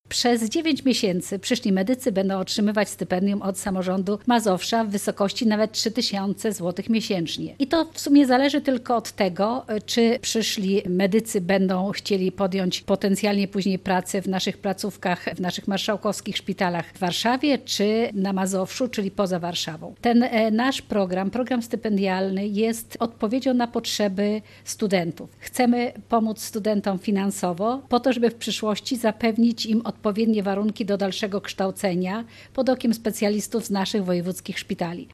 Członkini zarządu województwa mazowieckiego, Elżbieta Lanc mówi, że stypendia mają zachęcić do podjęcia pracy w szpitalach.